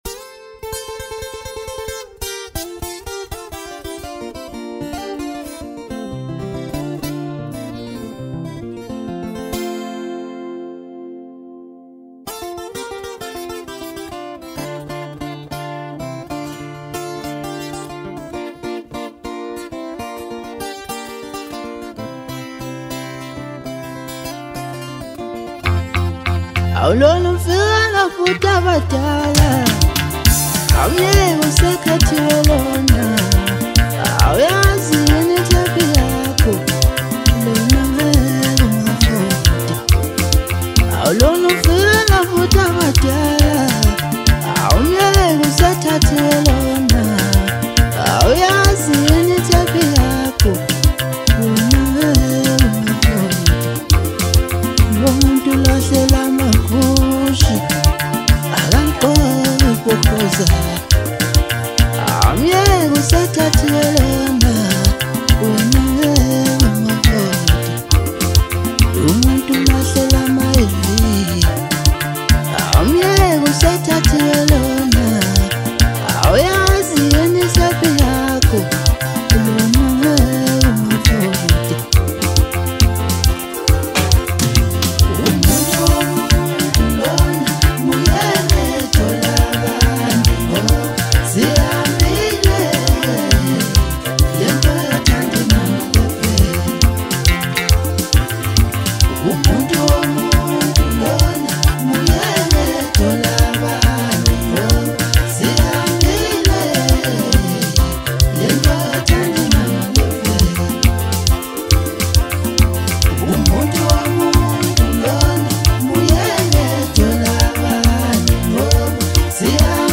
Foreign MusicSouth African